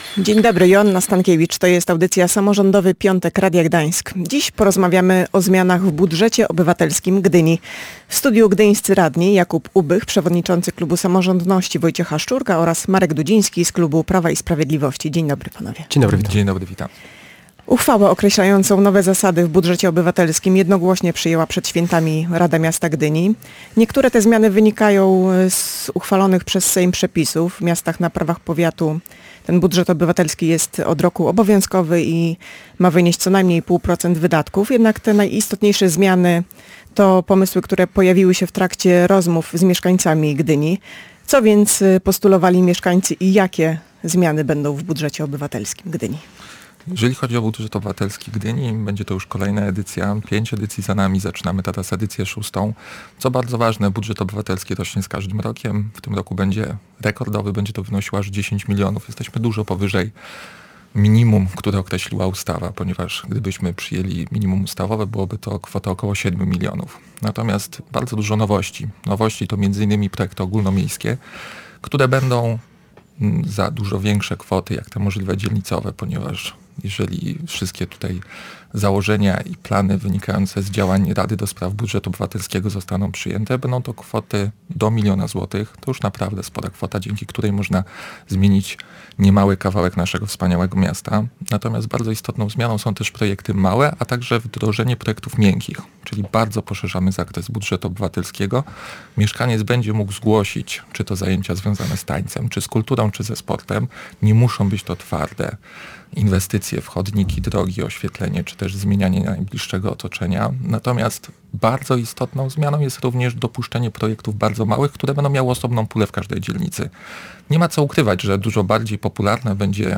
W studiu Radia Gdańsk dyskutowali gdyńscy radni: Jakub Ubych, przewodniczący klubu Samorządność Wojciecha Szczurka oraz Marek Dudziński z klubu Prawa i Sprawiedliwości.